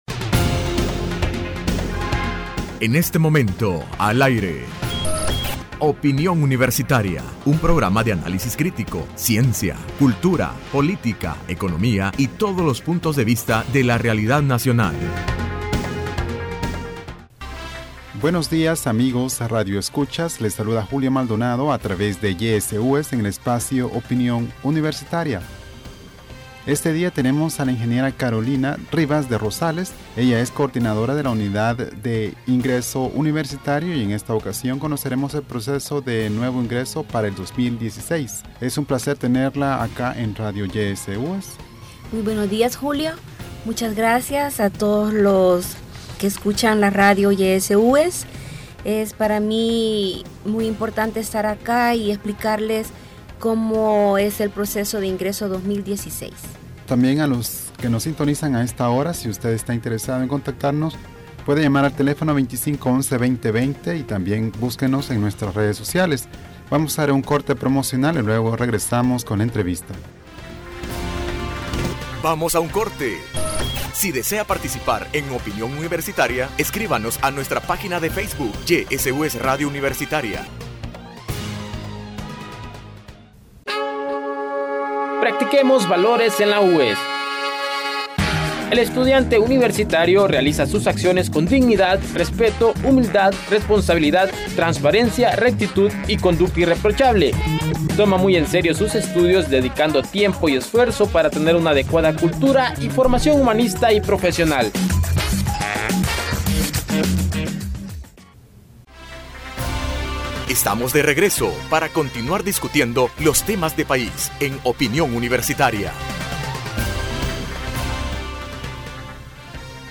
Entrevista Opinión Universitaria (7 mayo 2015): Proceso de ingreso universitario para el año 2016